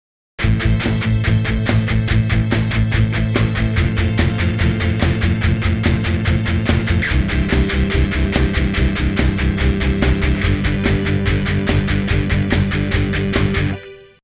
We will use the following chord progression: Am F C G.
In the first example the aim is to find the closest possible positions for the different chords, i.e. to move as little as possible.